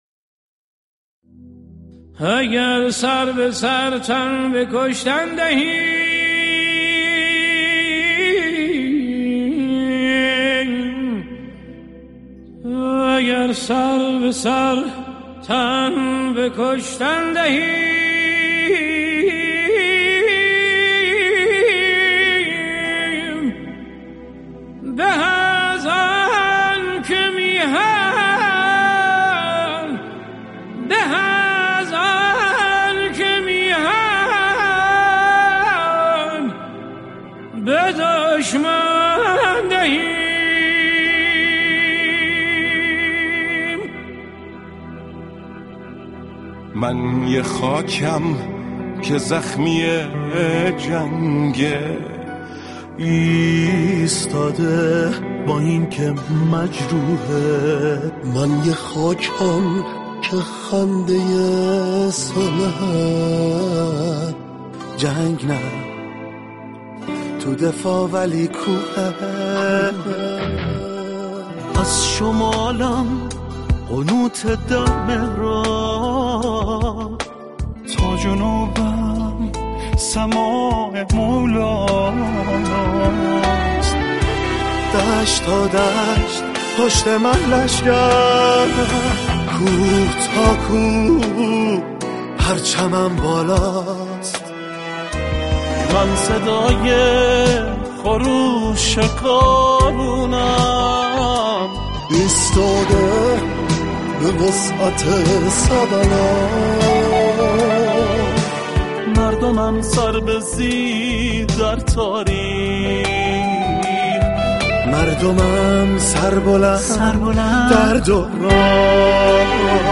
با همراهی 40 خواننده پاپ